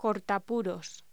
Locución: Cortapuros
voz